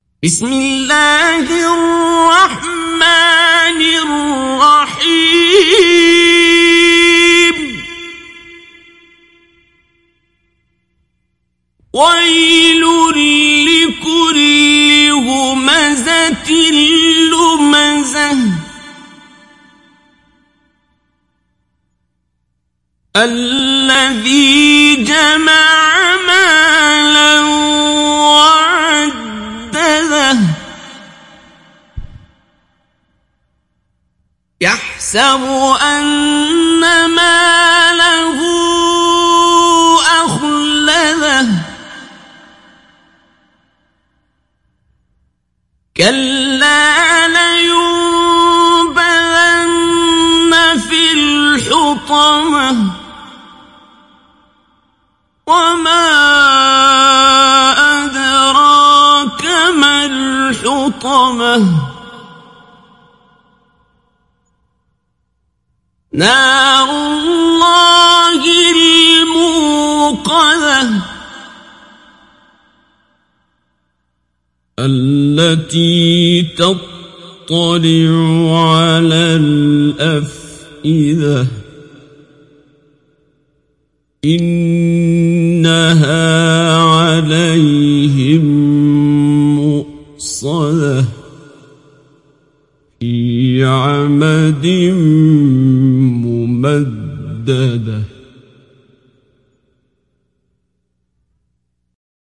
Humeze Suresi İndir mp3 Abdul Basit Abd Alsamad Mujawwad Riwayat Hafs an Asim, Kurani indirin ve mp3 tam doğrudan bağlantılar dinle
İndir Humeze Suresi Abdul Basit Abd Alsamad Mujawwad